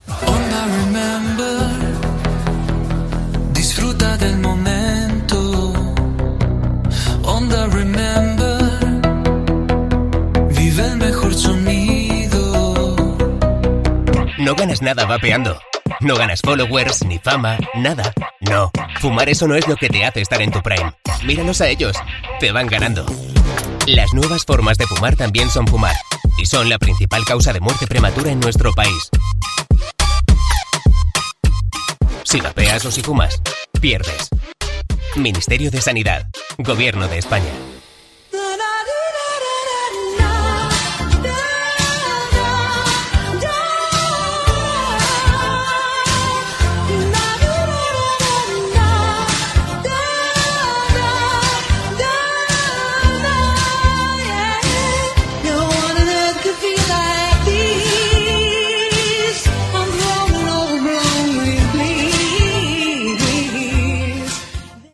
Indicatiu cantat de la ràdio, publicitat, tema musical.
Musical